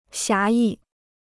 侠义 (xiá yì): chivalrous; chivalry.